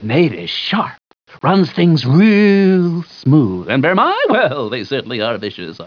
1. ^ Spider Daedra dialogue in Battlespire
BS-audio-SpiderDaedra_Vicious.wav